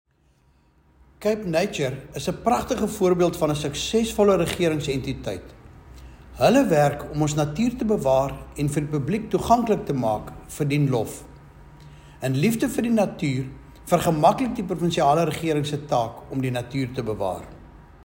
English soundbites from MPP Andricus van der Westhuizen attached.